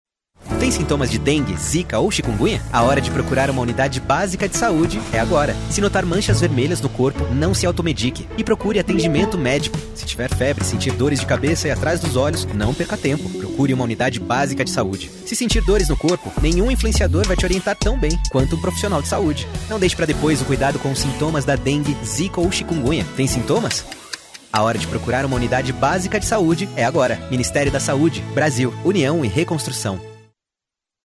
Spot - Campanha Fé no Brasil - Sudeste - RJ